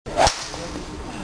とは言っても高い金属音ではないので、慣れてくればかなりいいと思いますが。
やっぱり300Sには消音剤がヘッドに使われているようでして、全然違います。